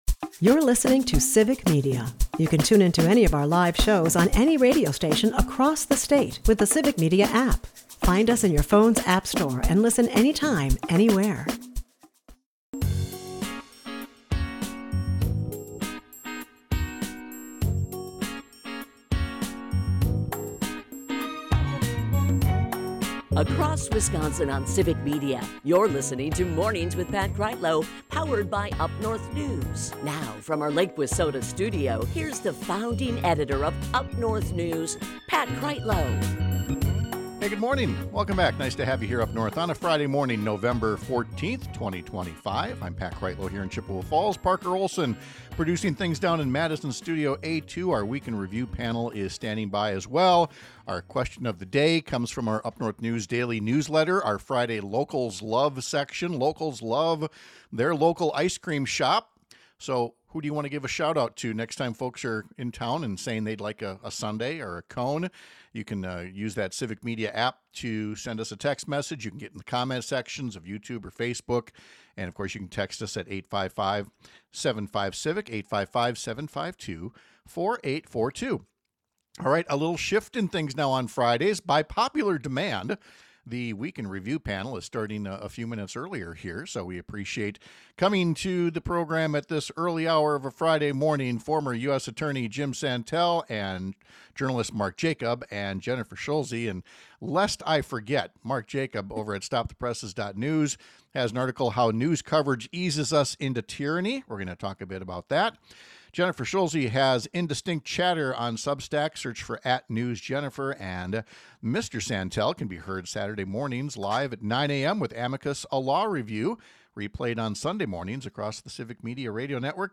Our Week in Review panel with former US Attorney Jim Santelle and veteran Chicago journalists